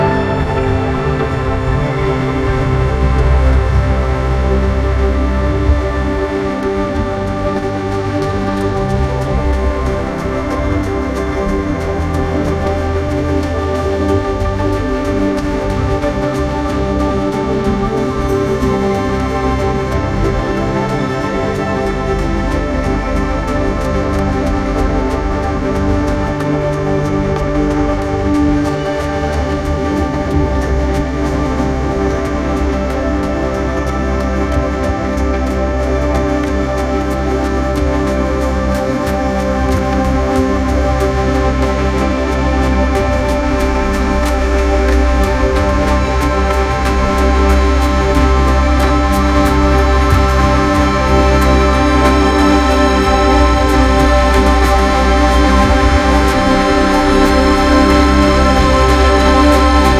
Generates new music tracks based on text prompts and audio inputs, with options for continuation or melody mimicry.
"prompt": "a flower blossoming",